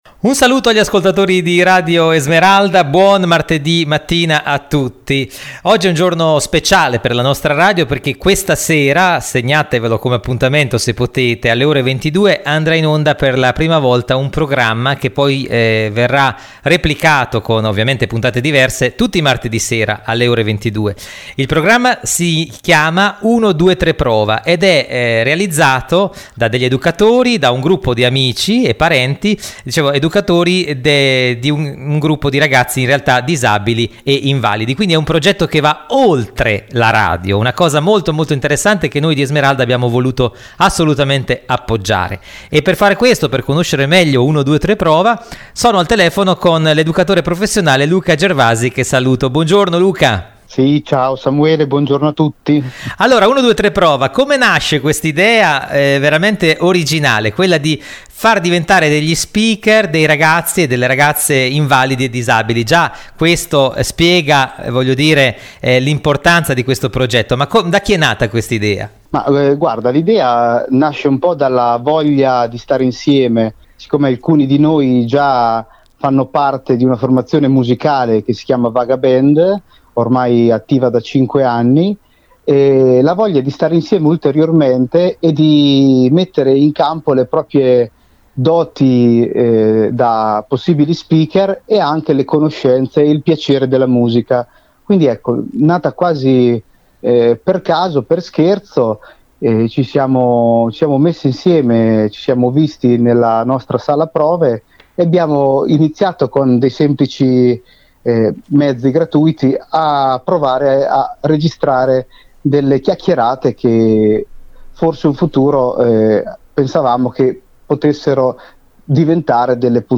Conosciamo meglio alcuni dei protagonisti in quest’ intervista.